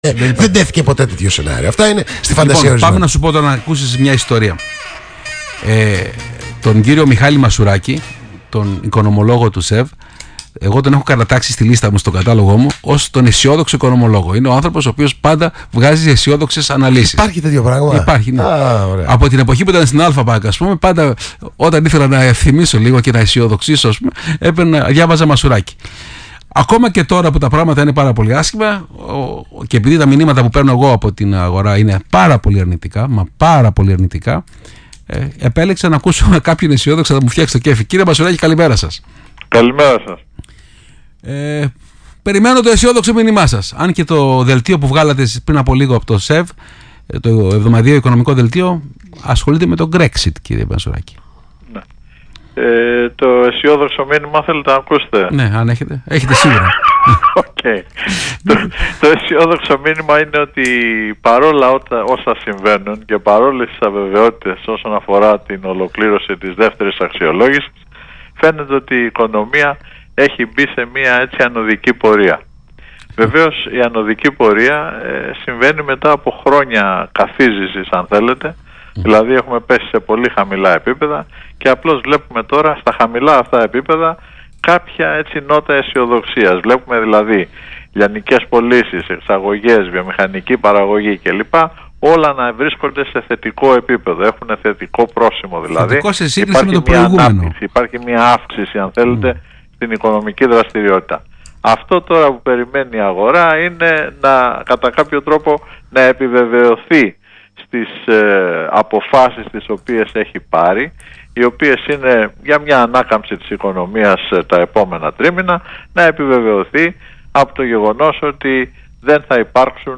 στον Ρ/Σ Action FM